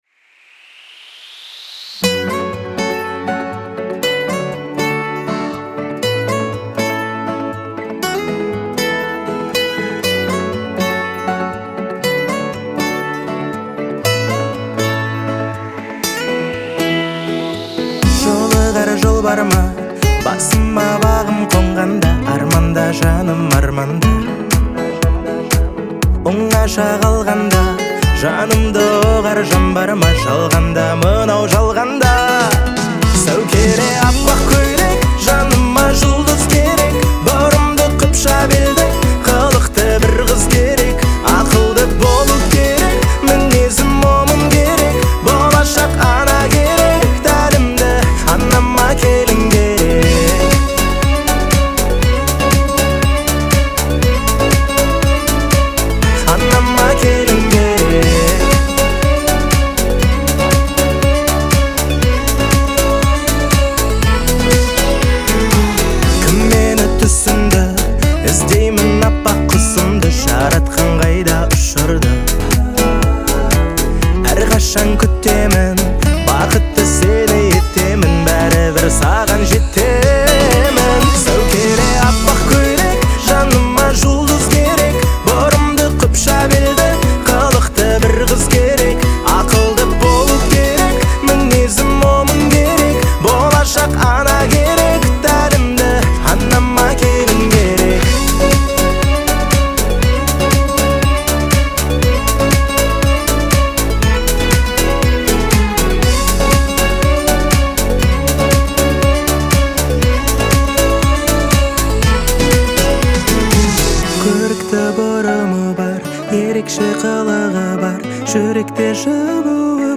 это трек в жанре поп с элементами народной музыки